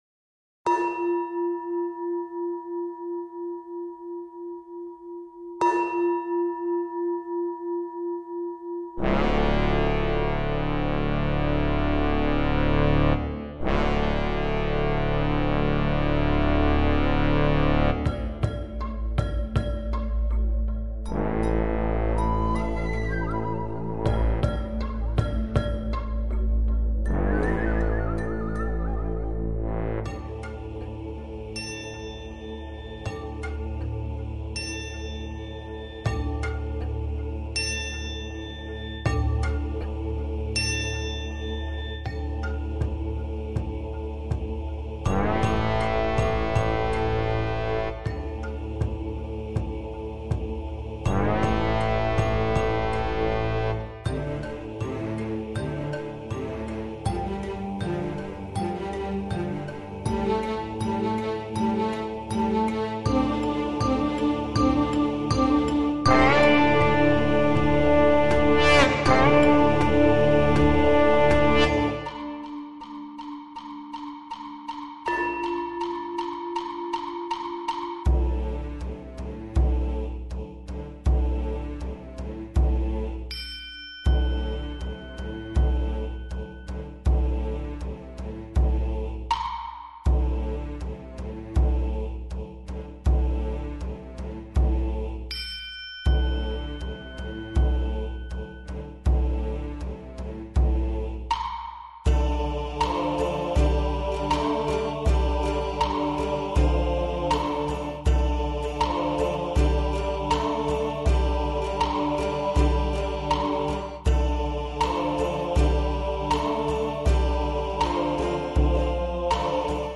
无 调式 : F 曲类